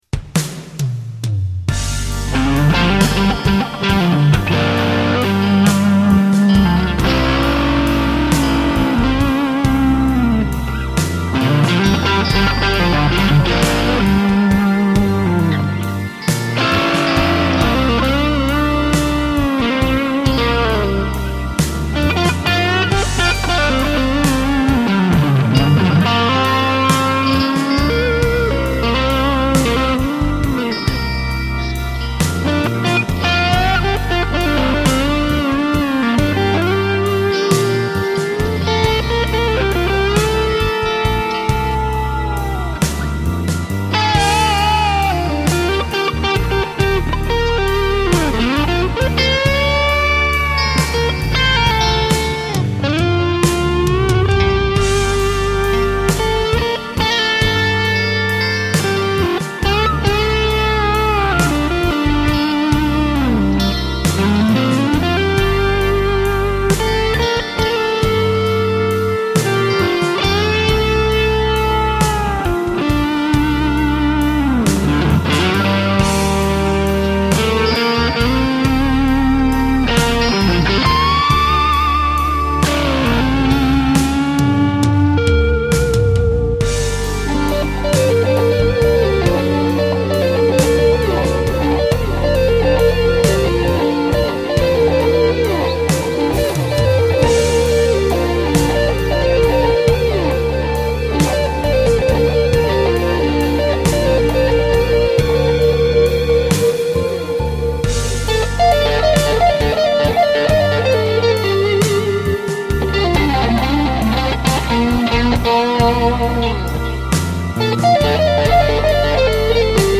PISTES AUDIO (guitares & basse) + MIDI
A nos Brunes (Blues-Rock . sur BT "Gilmour like")